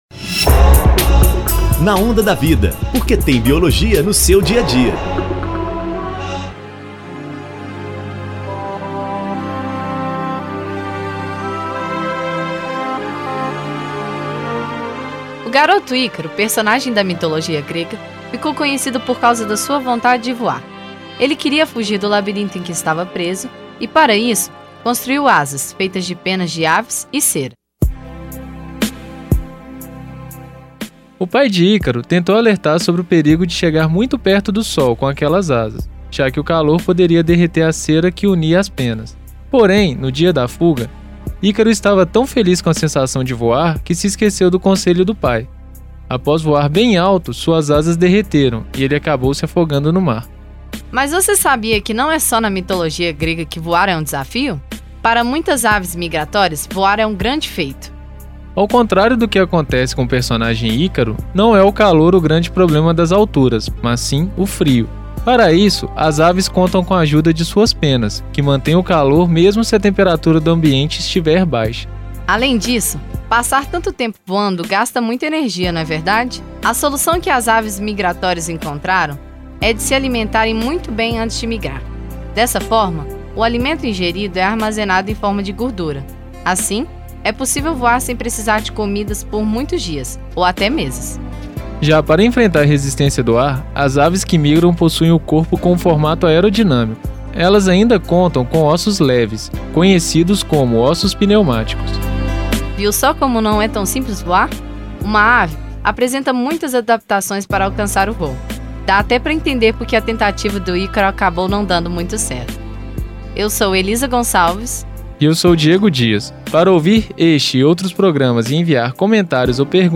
Na Onda da Vida” é um programa de divulgação científica através do rádio